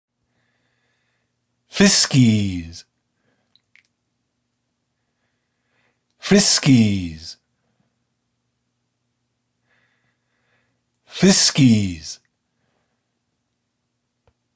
Regular French